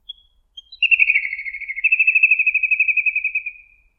카이츠부리1
little_grebe1.mp3